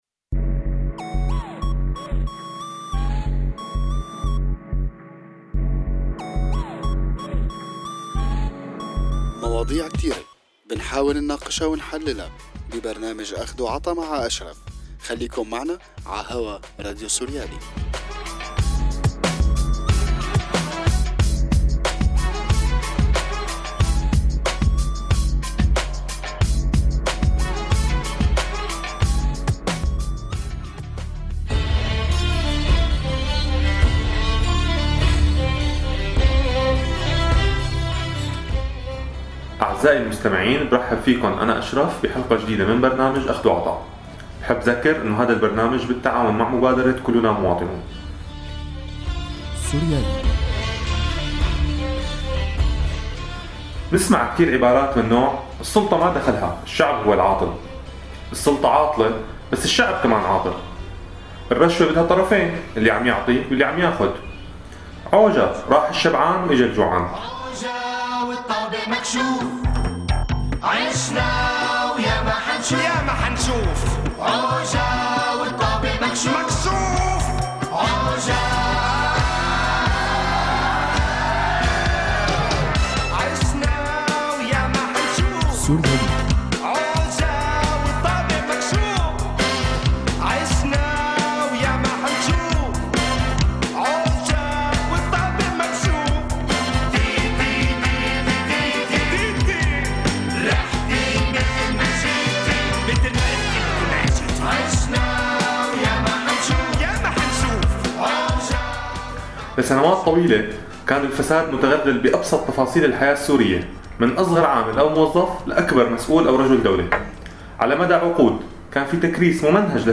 وشو الحل؟ضيف حلقة أخد وعطا كان الكاتب والروائي خالد خليفة…